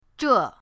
zhe4.mp3